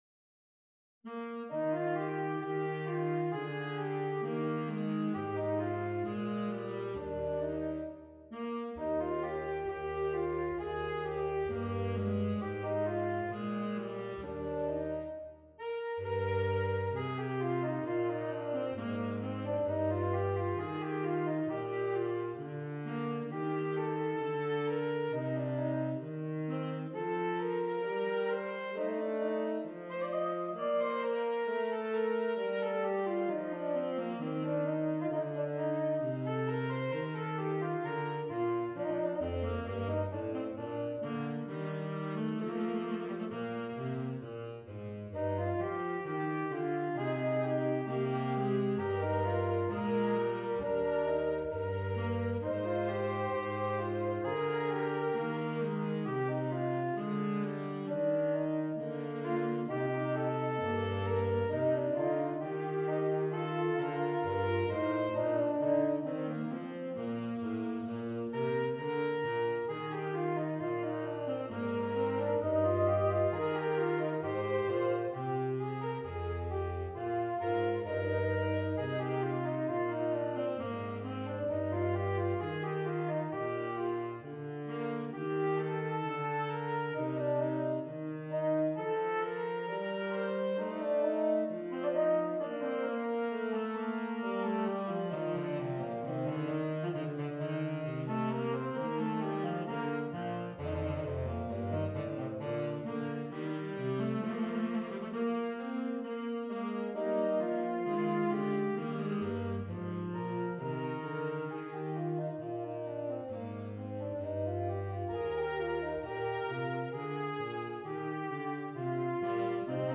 Saxophone Quartet (AATB)